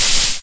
default_cool_lava.3.ogg